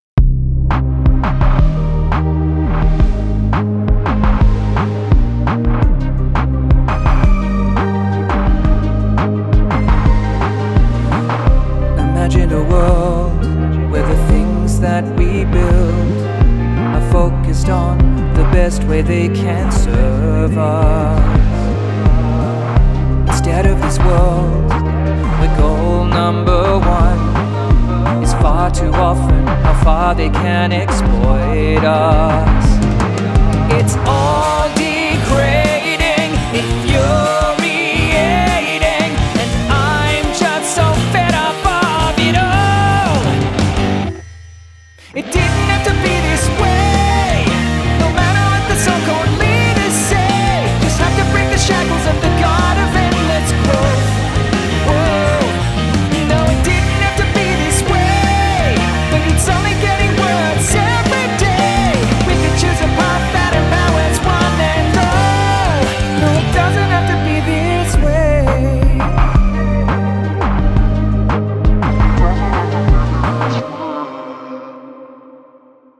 So after months of zero musical inspiration, I started writing a song about how utterly fed up I am of enshitification and hypercapitalism. Here's the intro, first verse, first chorus and interlude.